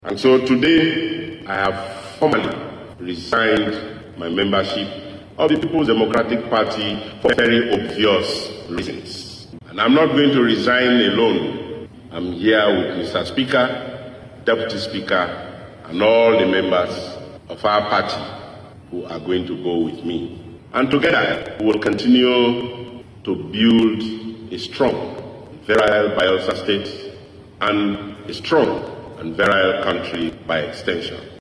Diri, in an announcement at the state house, explained that his decision was for an obvious reason, but did not state the reason or the political party to which he will be moving.